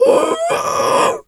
seal_walrus_2_death_01.wav